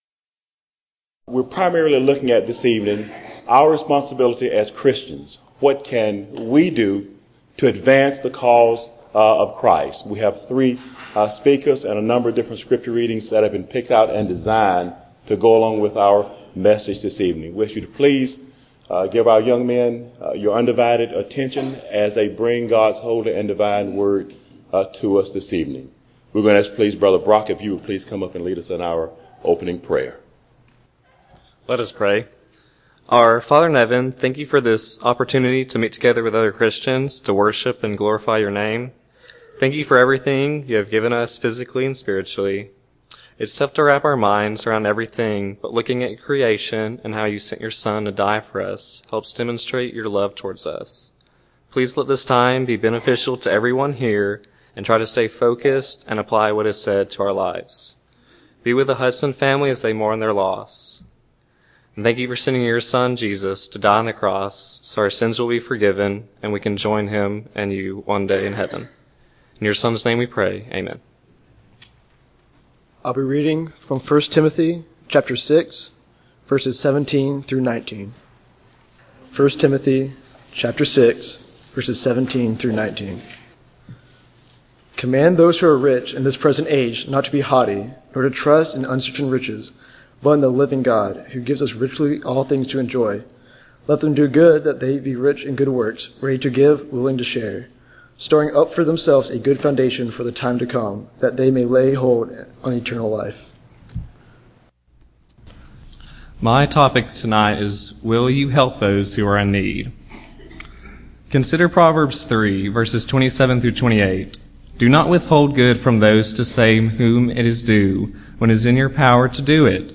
Service: Sun PM Type: Sermon Speaker: Various Young Men